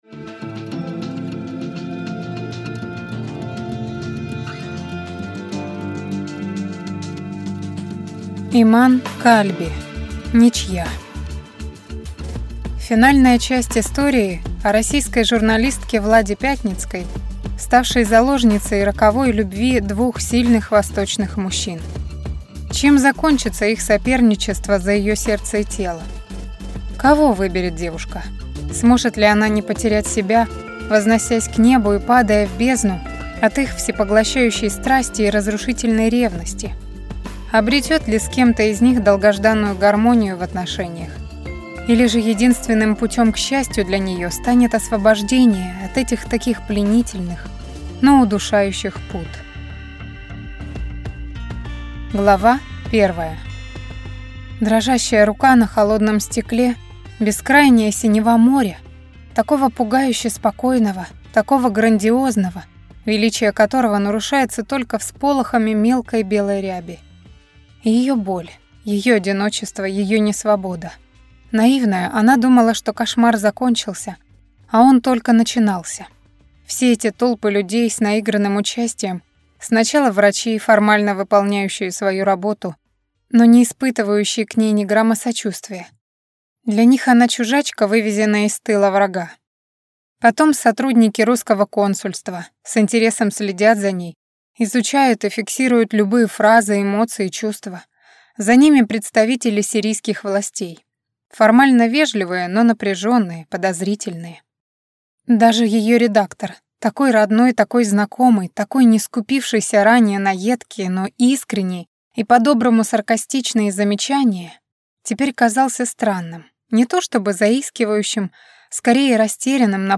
Aудиокнига Ничья…